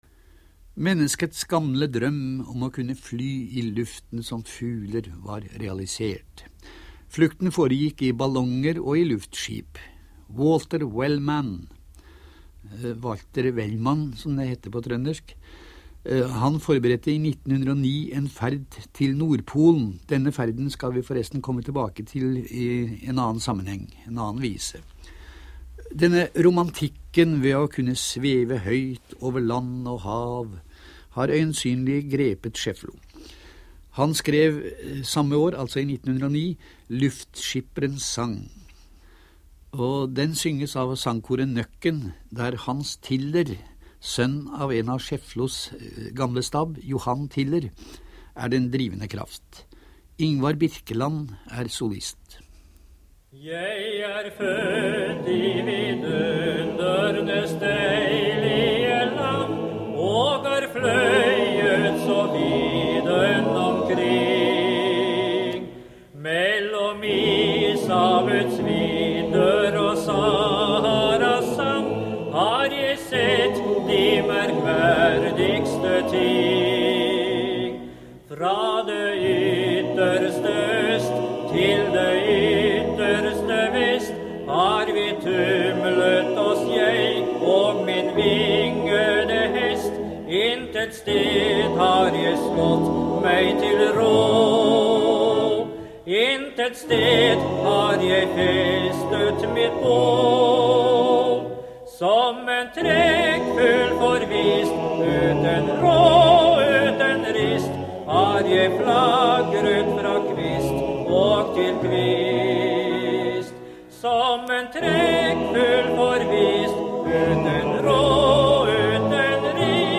På hundreårsdagen til Alv Schiefloe – 18. november 1973 – sendte NRK radio et minneprogram om Trondheims store vise- og revyforfatter.
som solist